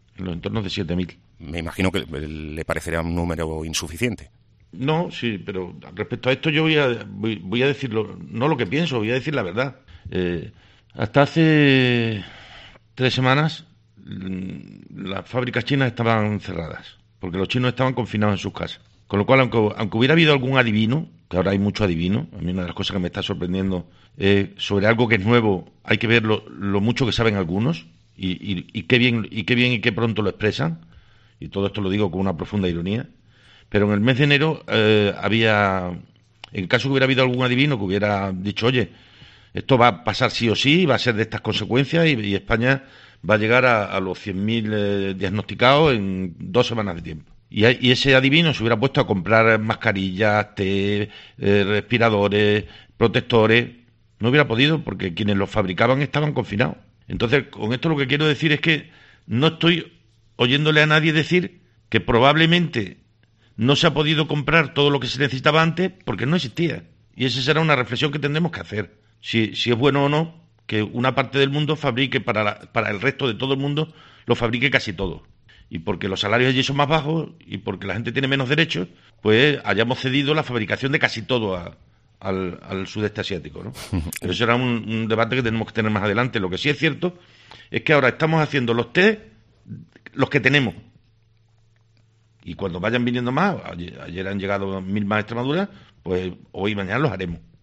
AUDIO: Fernández Vara en COPE Extremadura se refiere al número de test que se han realizado.